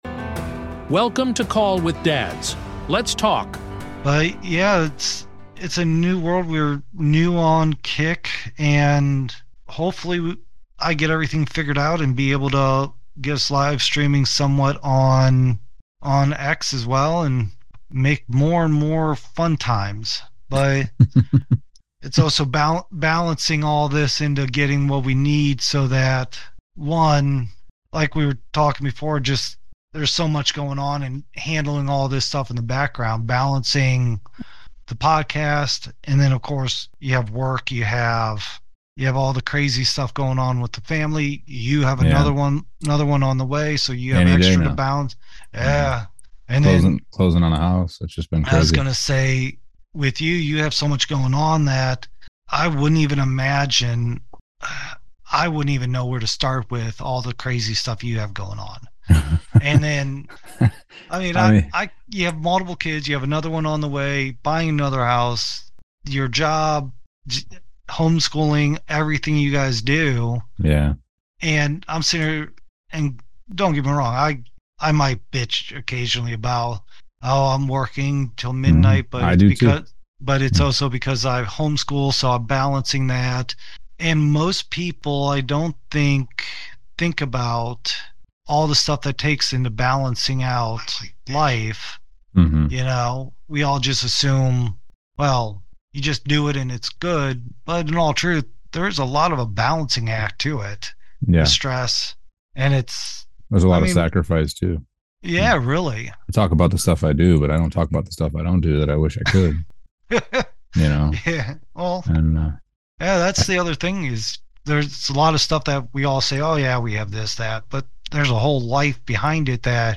Summary of the Discussion: (Leave blank for URL) Guest Spotlight: No guest this episode—just the raw, relatable insights from our core hosts as they reflect on fatherhood, priorities, and pushing through chaos.